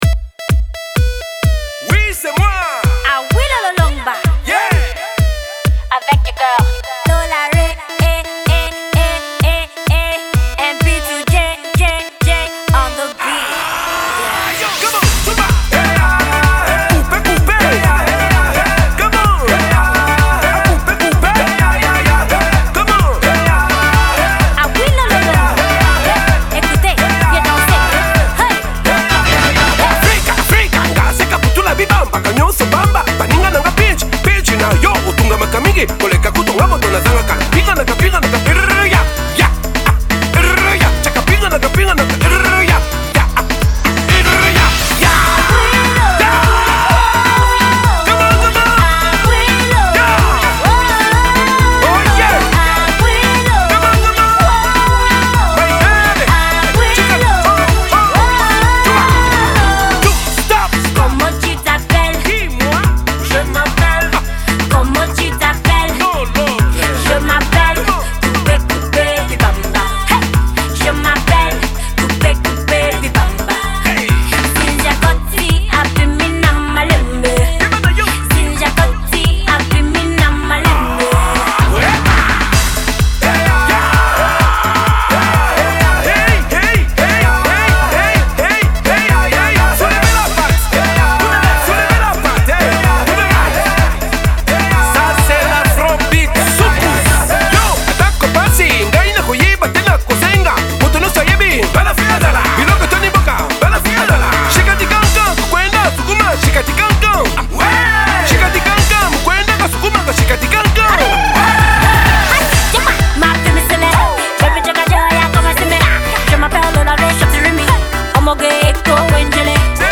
With all the Soukous-infused bangers of late
To give it a bit of a new school vibe